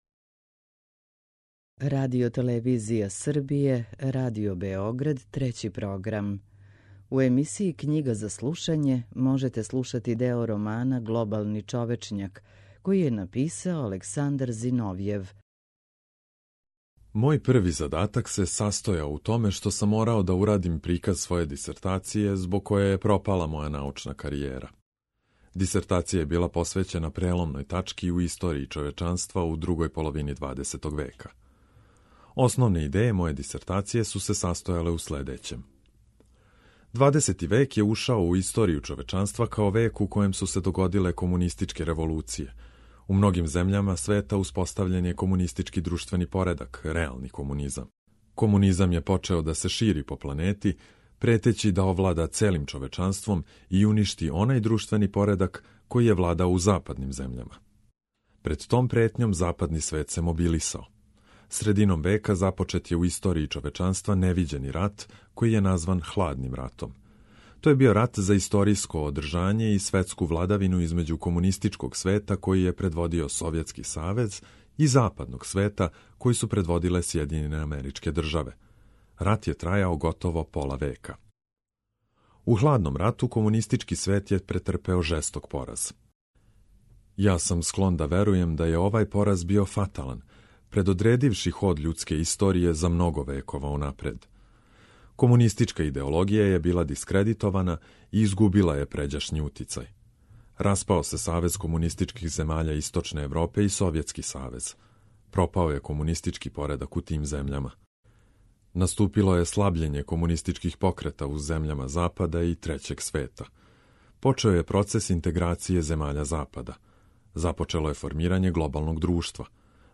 У емисији Књига за слушање можете пратити делове романа Александра Зиновјева „Глобални човечњак”.